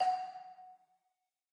marimba.ogg